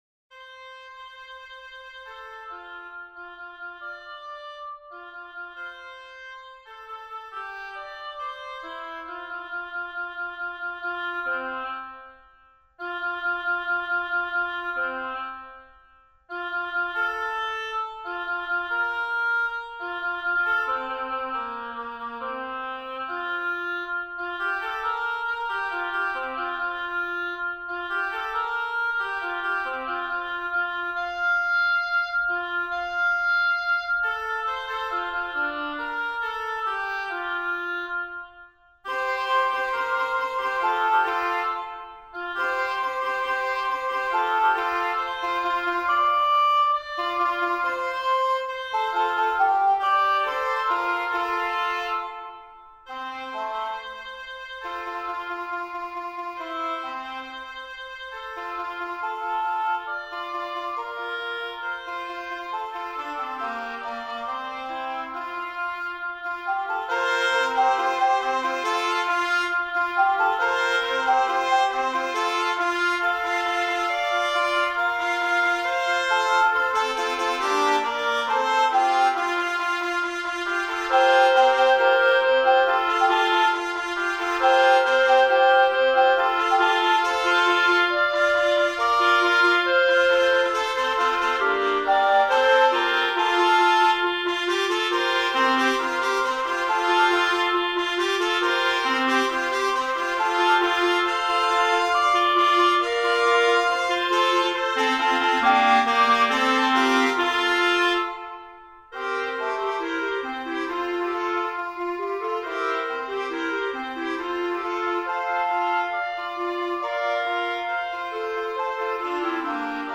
et finalement le canon complet résolu ouvert à quatre voix :
Vous remarquez que Beethoven transcrit le canon de sol majeur au fa majeur.
KV 560b  O du eselhafter Martin (Jakob)  Vierstimmiger Kanon